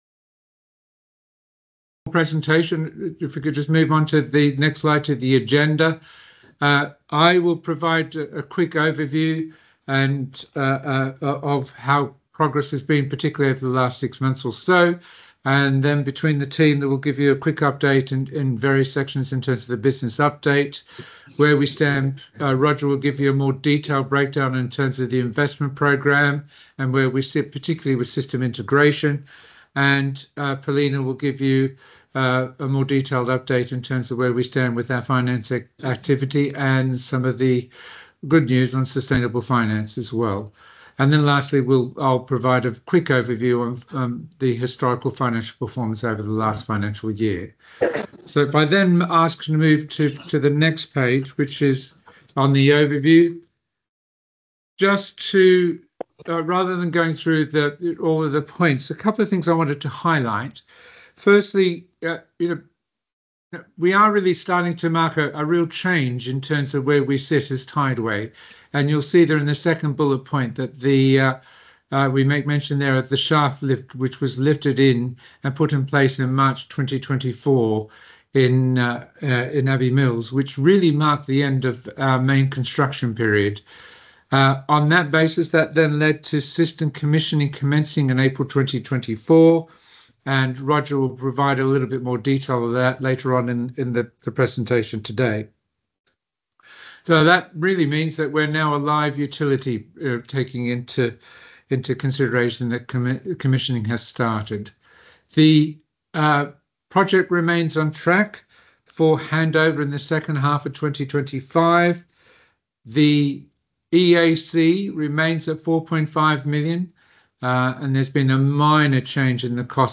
Investor calls